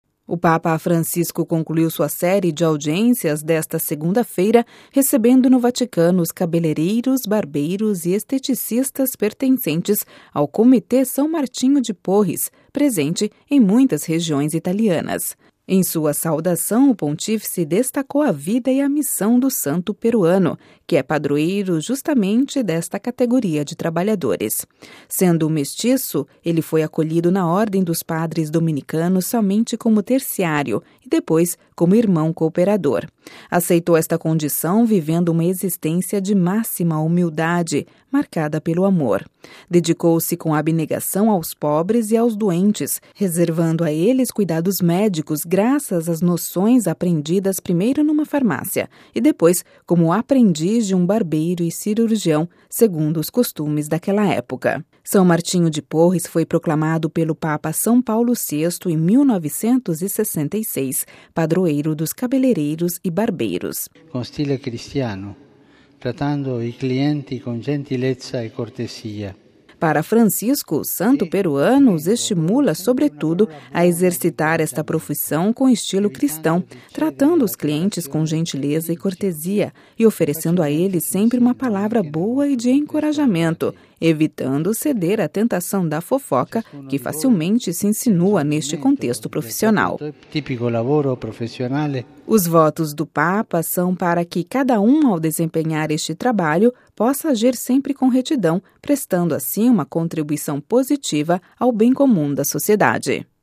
Ouça a reportagem com a voz do Papa Francisco